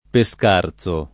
[ pe S k # r Z o ]